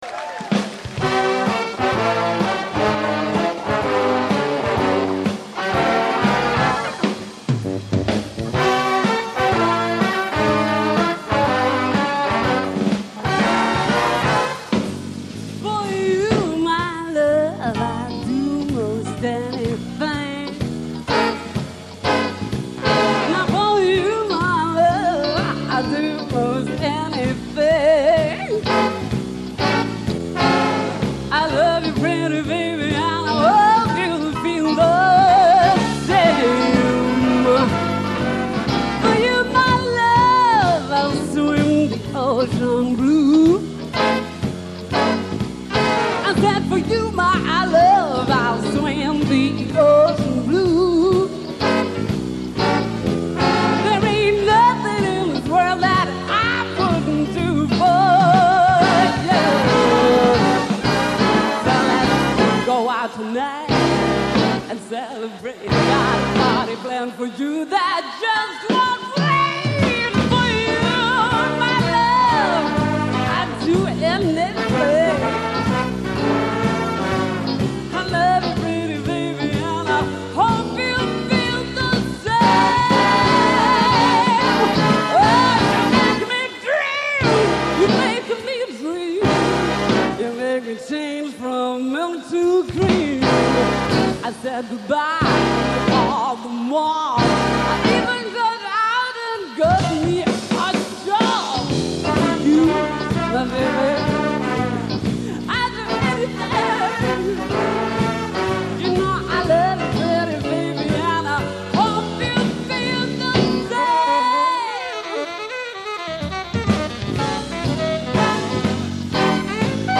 Vocal songs with Big Band Arrangements
5 Saxes, 4 Trumpets, 4 Trombones, Piano, Guitar, Bass, Drums
(Blues)